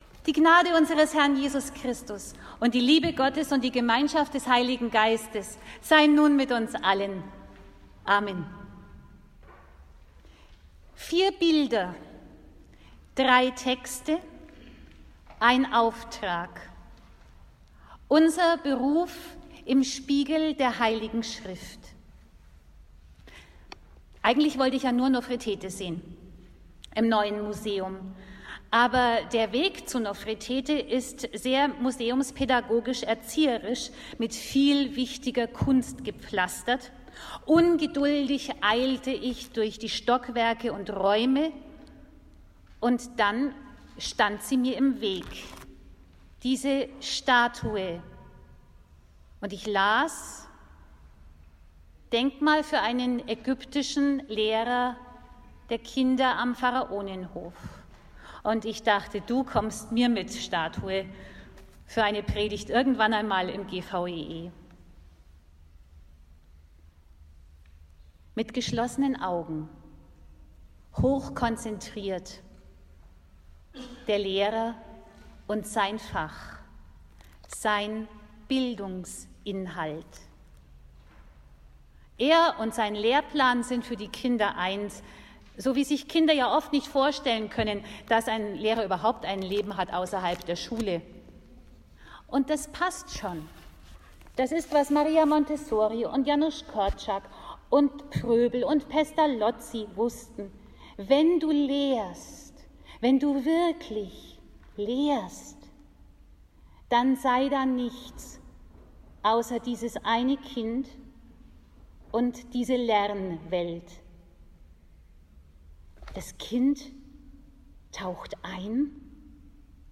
Der Predigt liegen zugrunde: Drei Texte: Alttestamentliche Lesung: aus Hesekiel 2,8-3,3 (Du Menschenkind!).